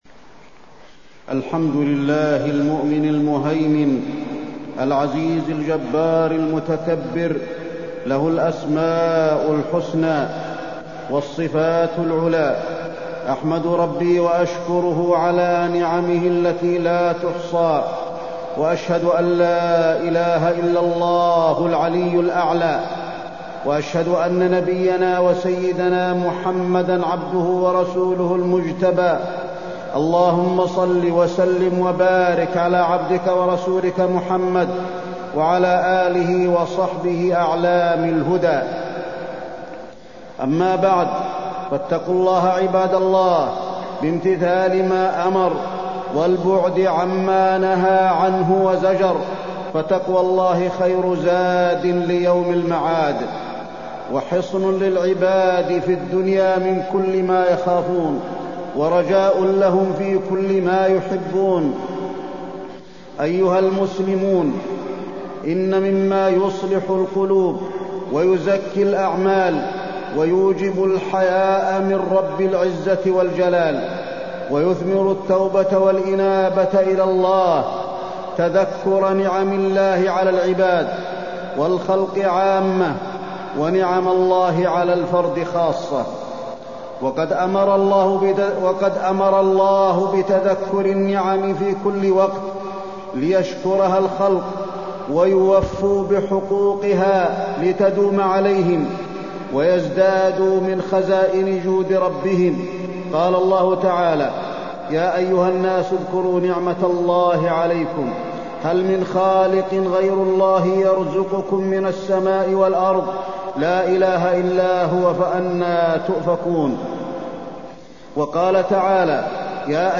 تاريخ النشر ١٣ ربيع الثاني ١٤٢٤ هـ المكان: المسجد النبوي الشيخ: فضيلة الشيخ د. علي بن عبدالرحمن الحذيفي فضيلة الشيخ د. علي بن عبدالرحمن الحذيفي فضل الدعاء